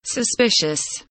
suspicious kelimesinin anlamı, resimli anlatımı ve sesli okunuşu